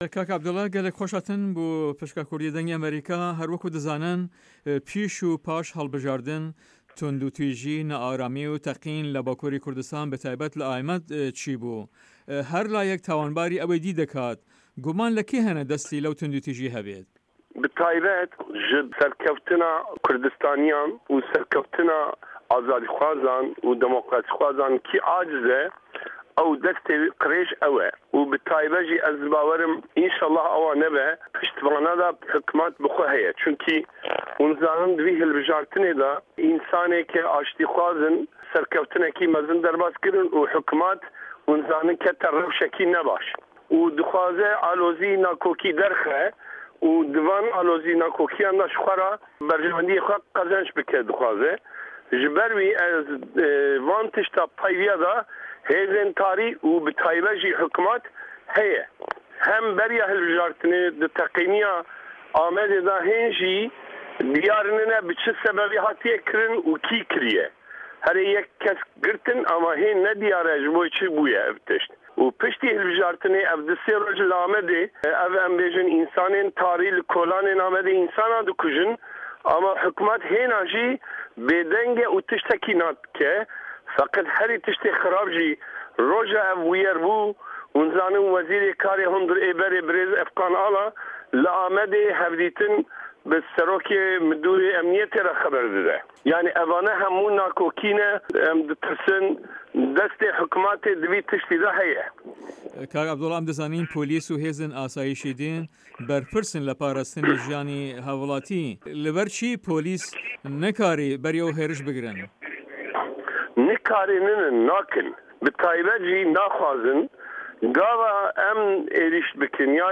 عه‌بدوڵا دیمیرباش ئه‌ندامی کۆنگریا جڤاکن دیموکراتیکا کوردستان( KCD ) و شارداری پێشووی شارداری سوری/ ئامه‌د له‌ هه‌ڤپه‌یڤینێکدا له‌گه‌ڵ به‌شی کوردی ده‌نگی ئه‌مه‌ریکا ده‌ڵێت" به‌ تاێبه‌ت ئه‌و که‌سانه‌ی له‌ سه‌رکه‌وتنی کوردستانیه‌کان، له‌ سه‌رکه‌وتنی دیموکرات و ئازادی خوازاندا عاجزن، ئه‌وانه‌ن له‌و کارانه‌دا ده‌ستیان هه‌یه‌، وه‌ به‌ تاێبه‌ت من له‌و بروایه‌دام وه‌ ئومێده‌وارم وا نه‌بێت حکومه‌ت خۆێ ده‌ستی تێدایه‌.